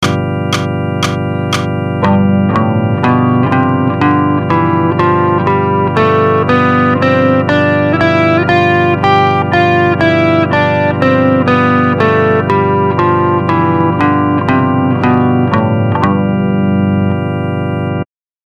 Mixolydian Mode
The G Mixolydian creates a great jazz/fusion sound very similar to Jeff Beck.
g_mixolydian.mp3